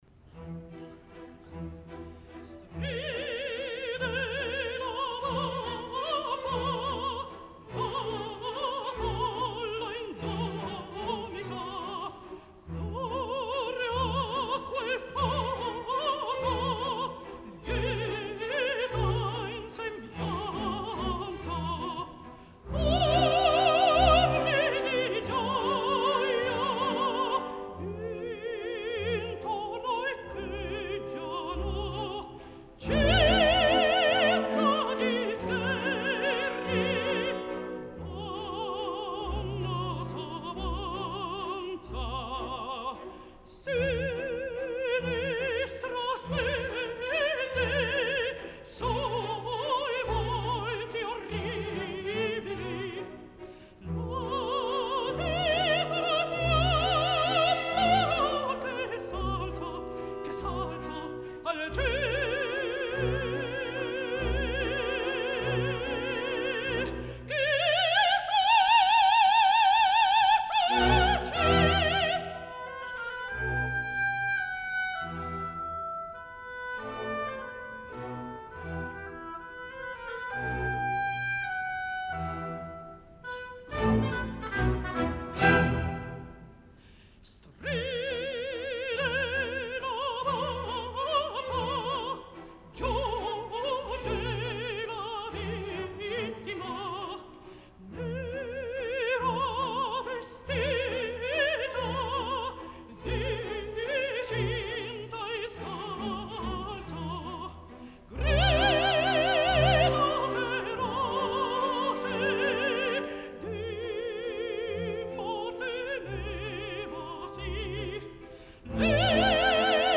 Violeta Urmaana (Azucena) a Il Trovatore a Amsterdam 2015.
mezzosoprano